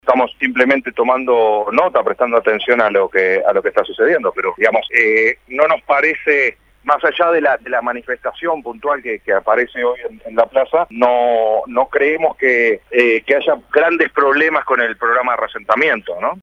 En declaraciones recogidas por Radio Montecarlo, Miranda dijo que la idea de no haber traído a todas las familias juntas cuando se previó esto era aprender a partir de las experiencias.